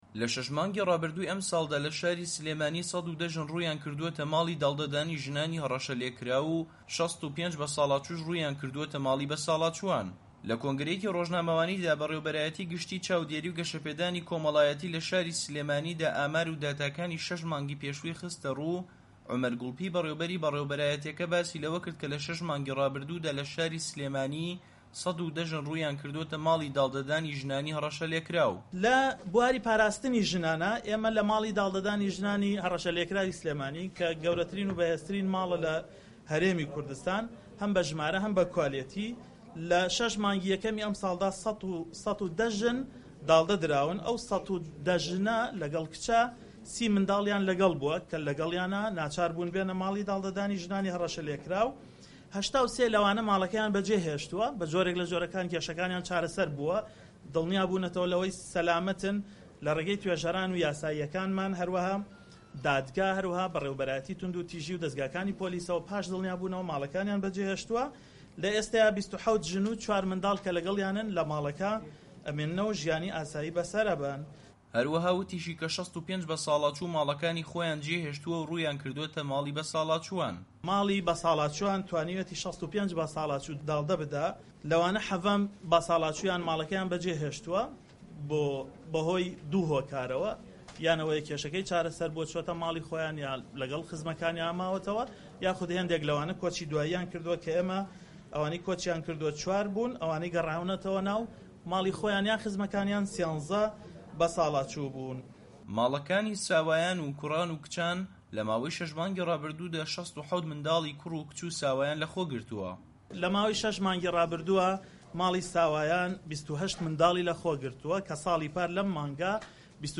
کۆنفرانسی ڕۆژنامەوانی